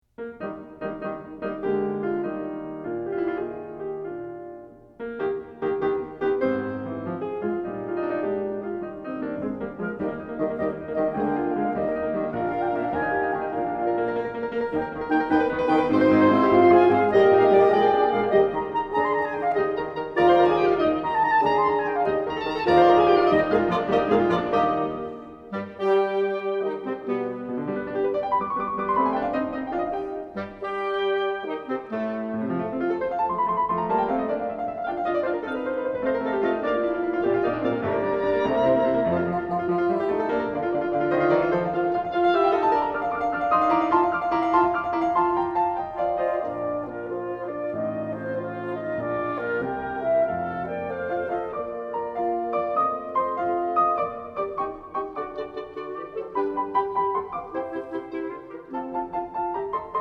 Oboe
Clarinet
Horn
Bassoon